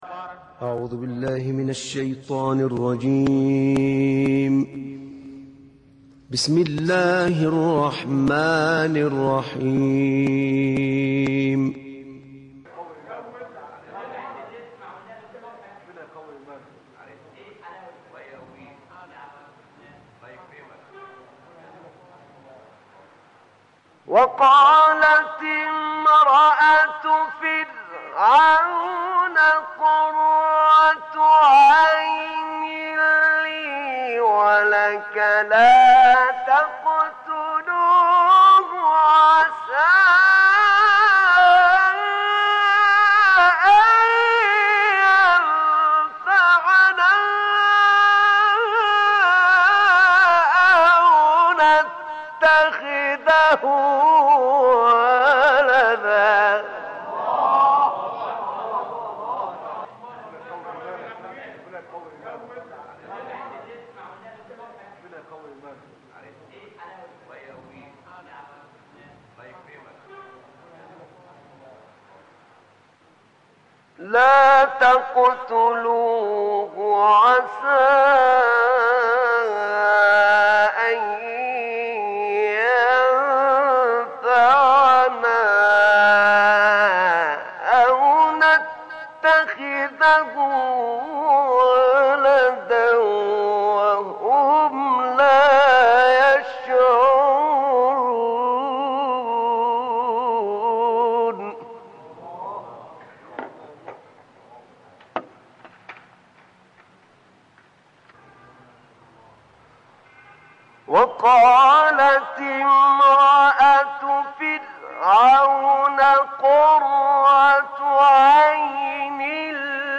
تلاوتی زیبا و ماندگار از استاد احمد نعینع ... آیات 9 تا 24 سوره قصص